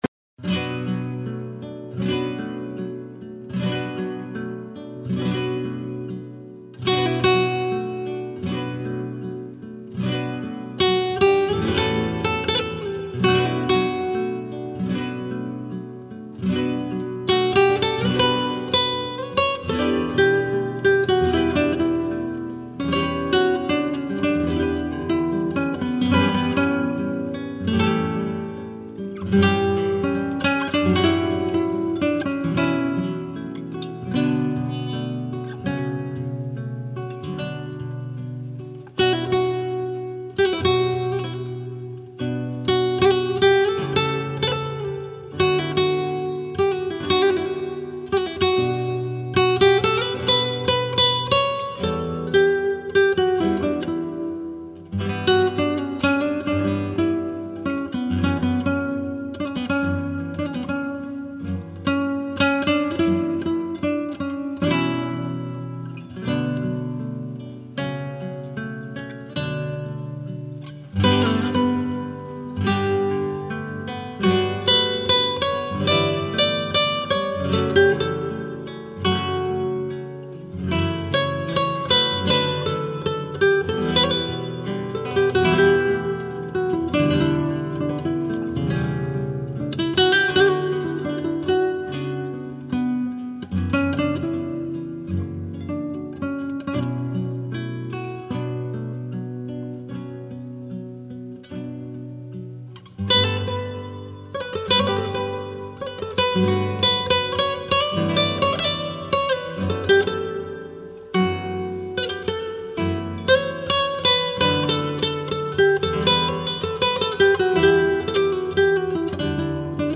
гитара (закрыта)